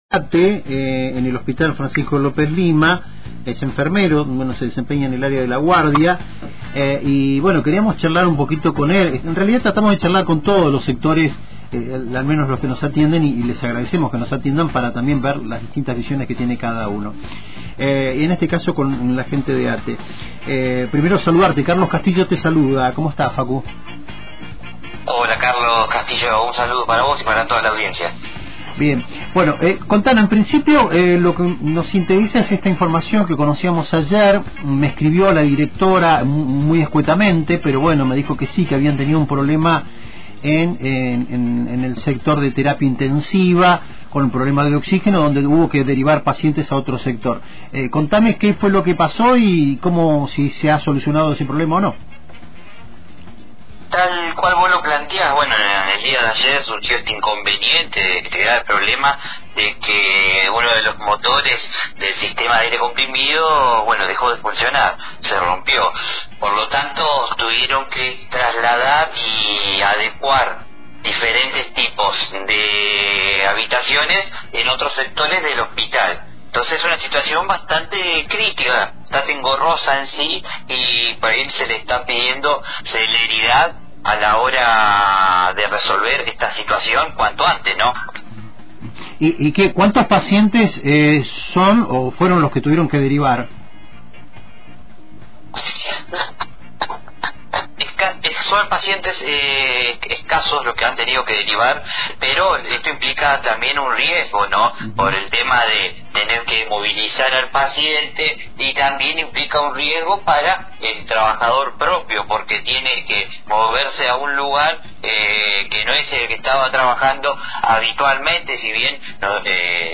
En diàlogo con la radio